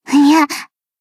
BA_V_Ui_Swimsuit_Battle_Shout_3.ogg